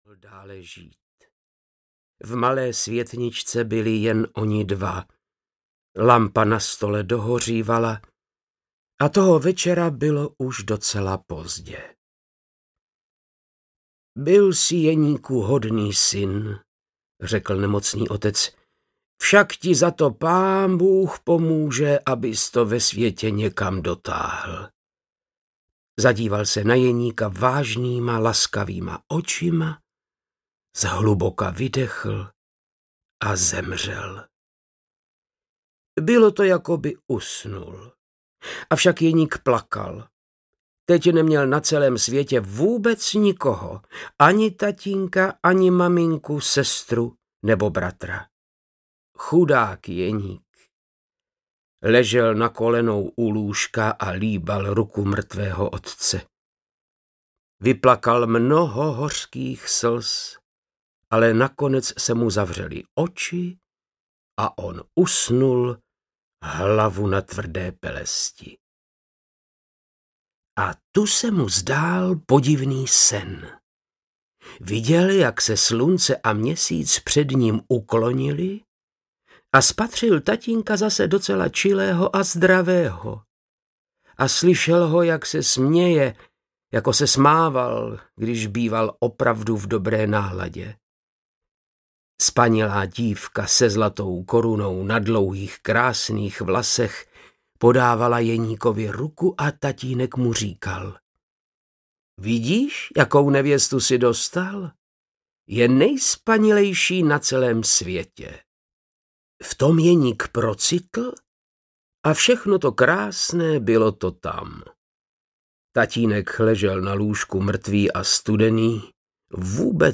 Přítel na cestách audiokniha
Ukázka z knihy
• InterpretVáclav Knop